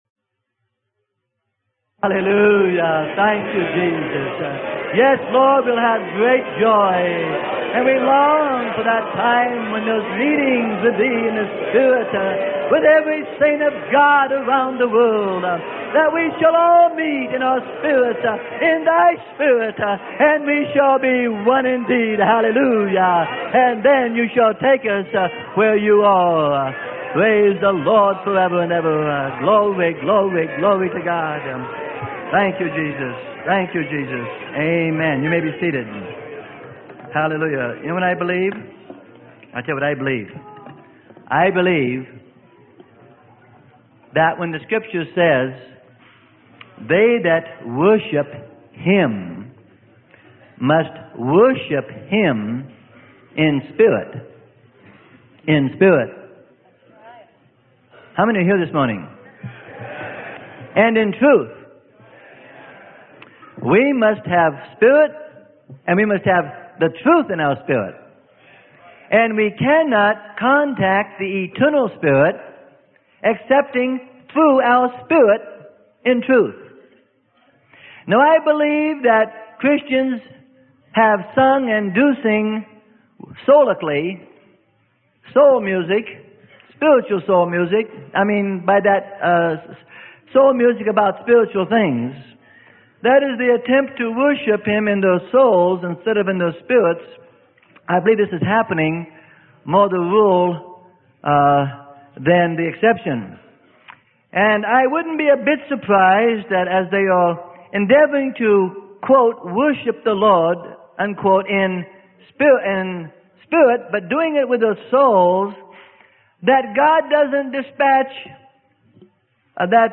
Sermon: Thinking Straight Concerning Spiritual Operations - Freely Given Online Library